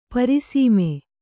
♪ pari sīme